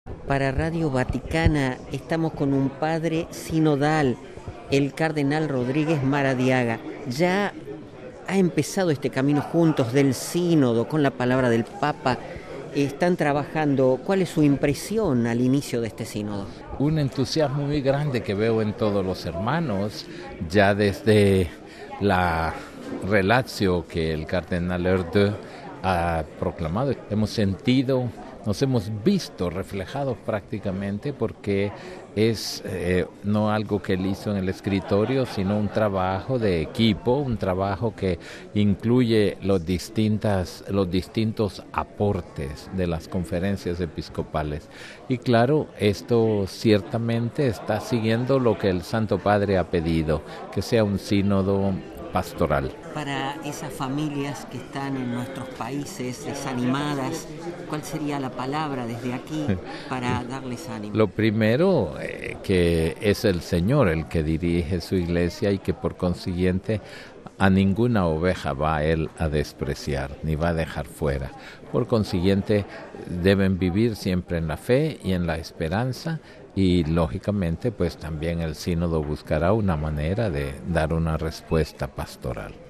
(RV).- (con audio) El cardenal hondureño confirma el entusiasmo que hay entre los Padres Sinodales y advierte de lo importante que es el trabajo en equipo desde las distintas Conferencias Episcopales. Óscar Andrés Rodríguez Maradiaga, además, durante su entrevista para Radio Vaticano asegura que seguirán la exhortación del Papa cuando les pidió que sea un “sínodo pastoral”.